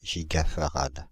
Ääntäminen
France (Île-de-France): IPA: /ʒi.ɡa.fa.ʁad/